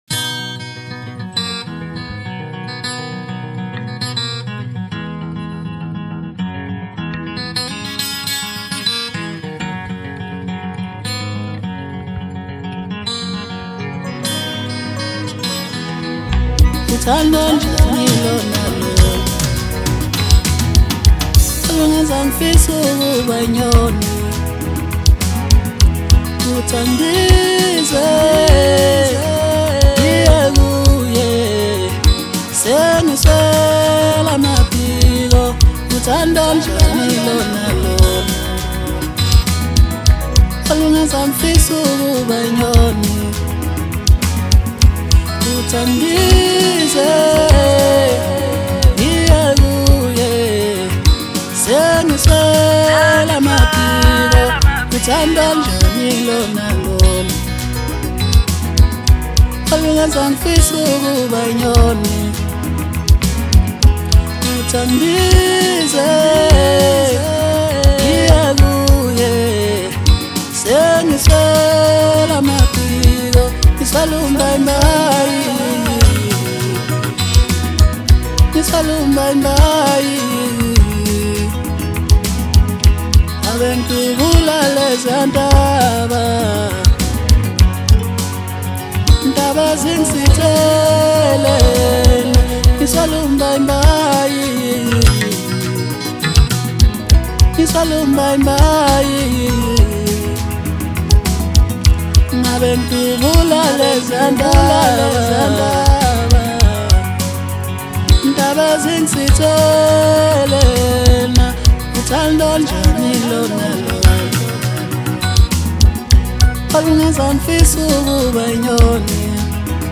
Home » Maskandi » DJ Mix » Hip Hop
Listeners are treated to a refreshing vibe